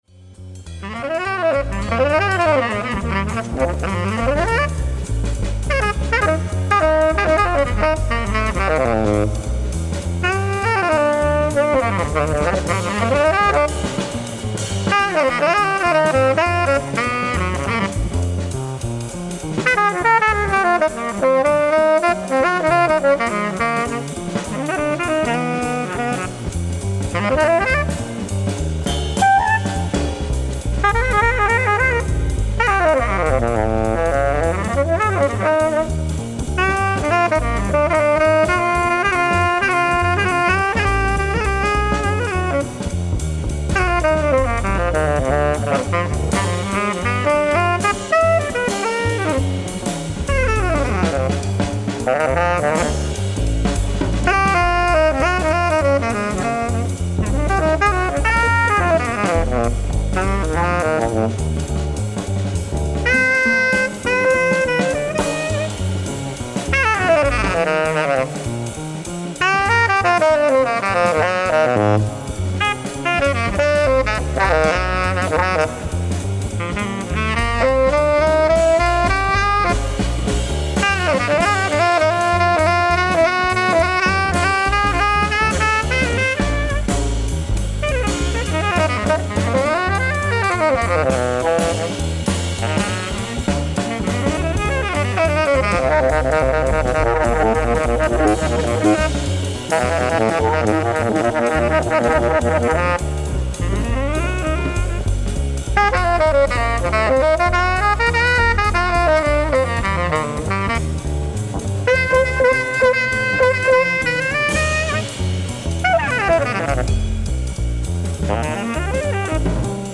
ライブ・アット・ジャズ・マルシアック、マルシアック、フランス 08/12/1994
尚、途中テープ・ヒスが若干生じる箇所が若干御座います。
※試聴用に実際より音質を落としています。